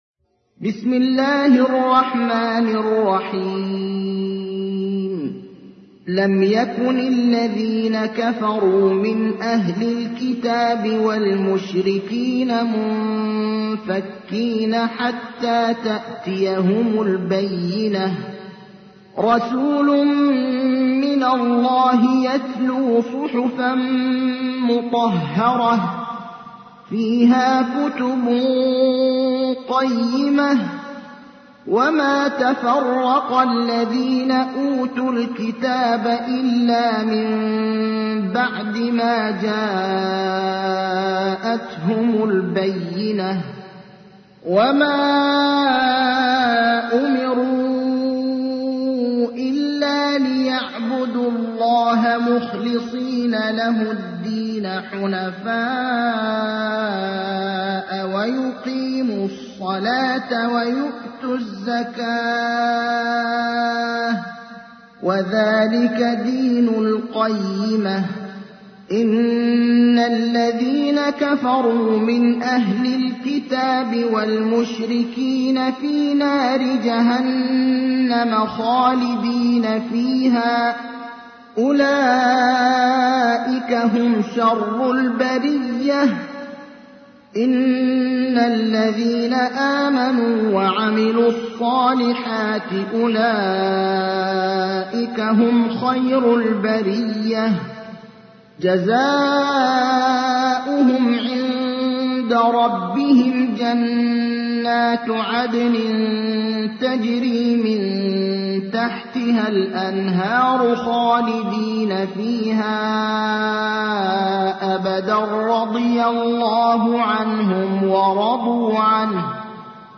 تحميل : 98. سورة البينة / القارئ ابراهيم الأخضر / القرآن الكريم / موقع يا حسين